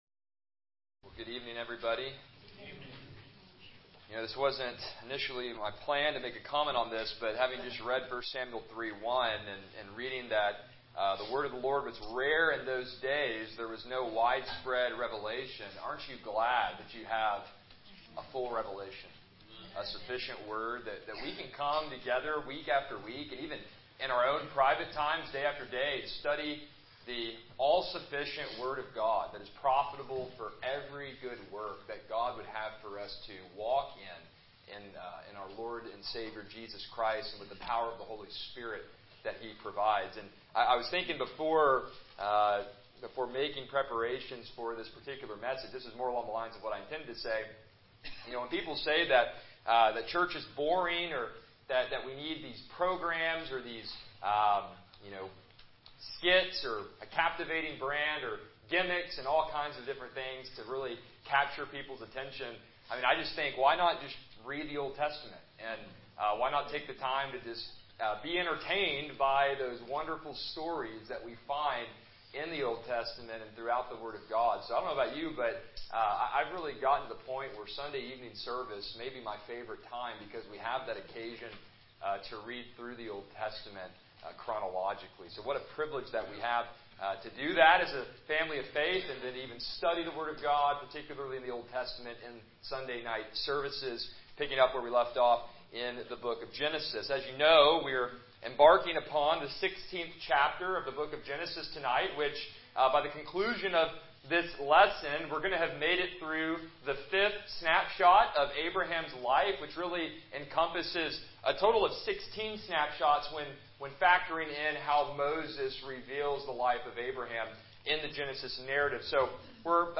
Passage: Genesis 16 Service Type: Evening Worship